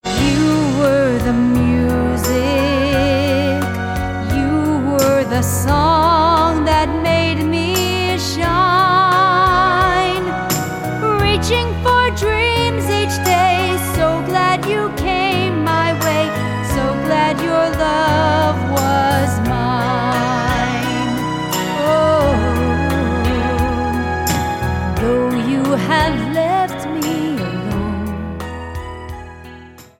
Vocal mp3 Track